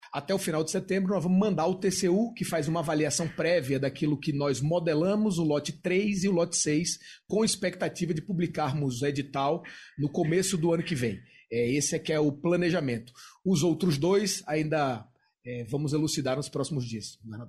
RENAN FILHO - COLETIVA.mp3